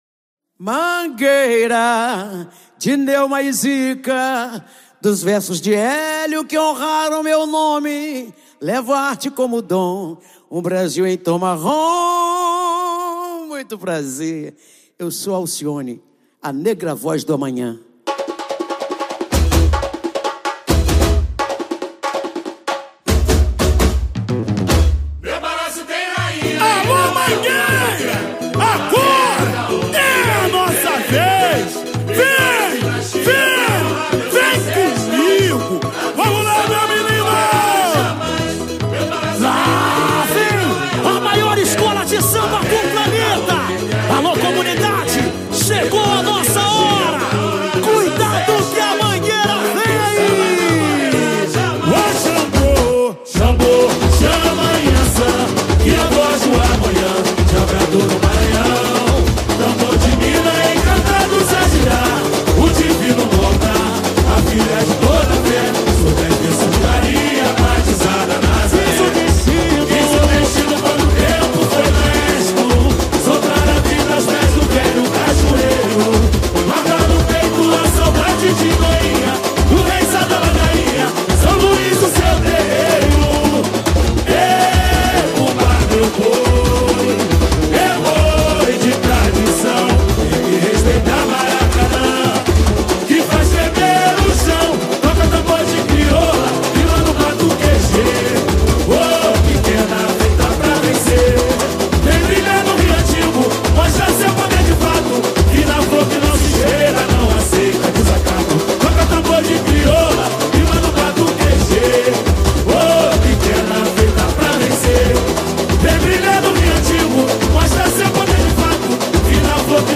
Samba Enredo 2024